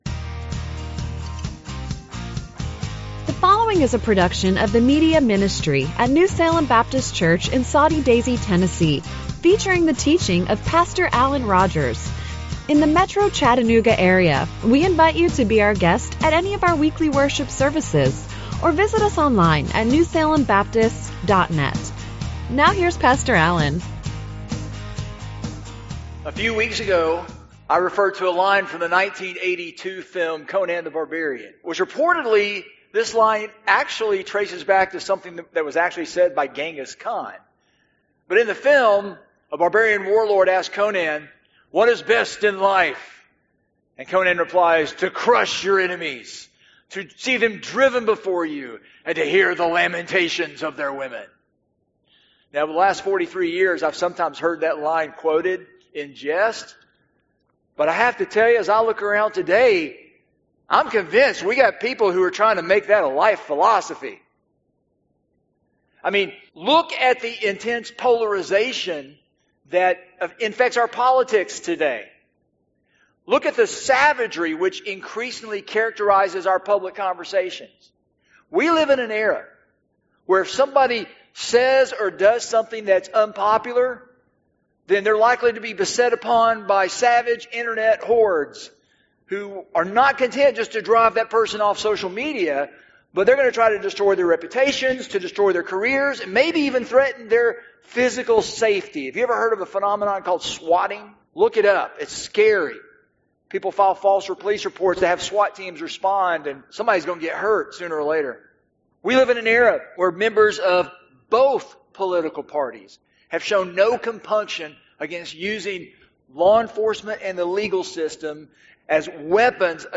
Genre: Sermon.